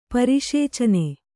♪ pariṣēcane